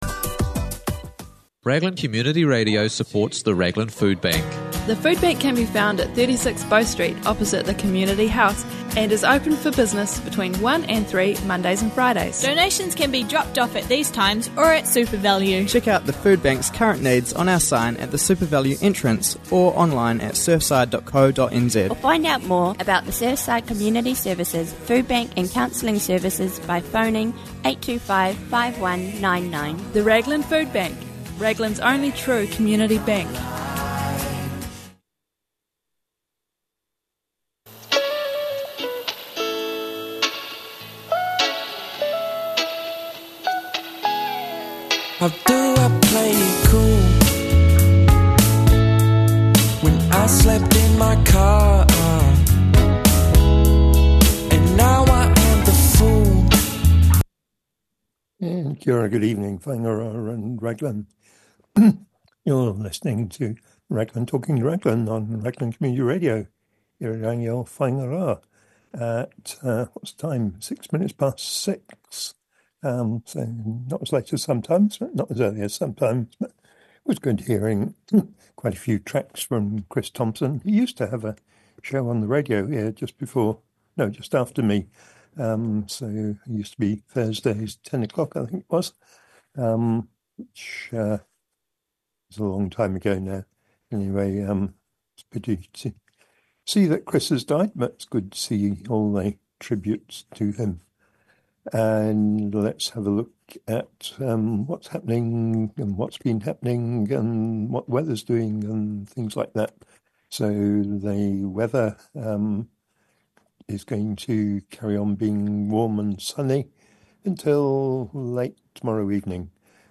Christmas Eve, but still there's news and Morning Show interviews and another week of 2024 to go.